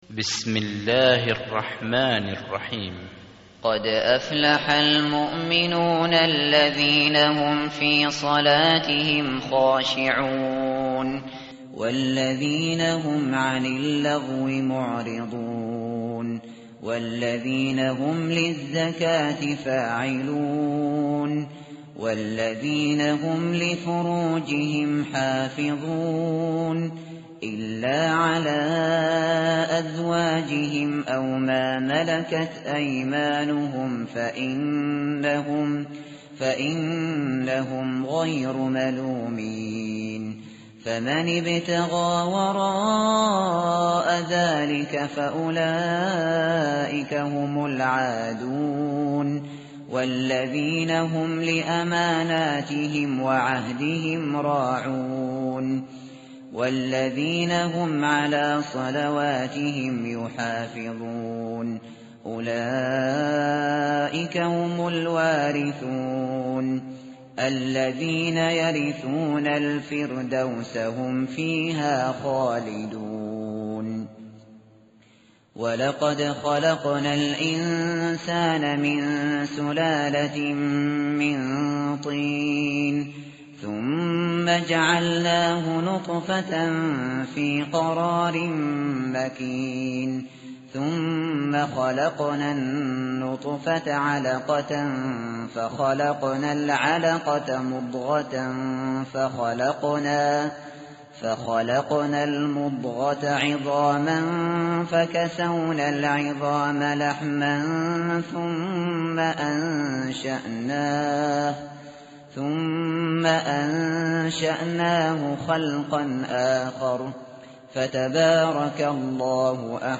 tartil_shateri_page_342.mp3